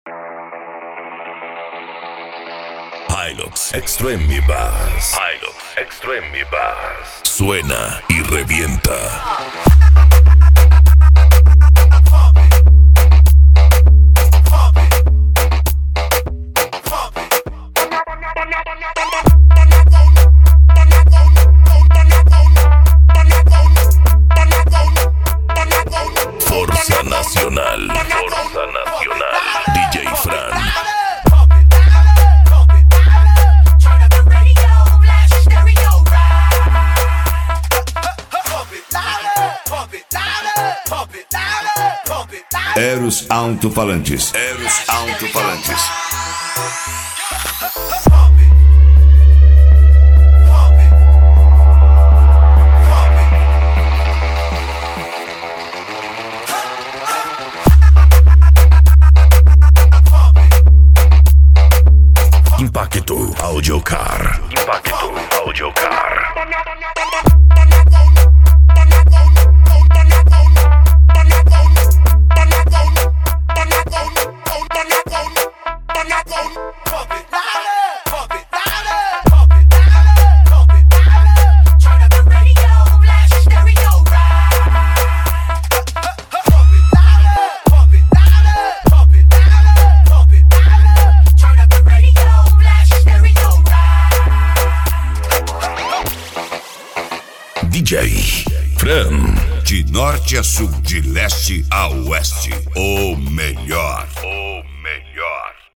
Bass
Eletronica
Remix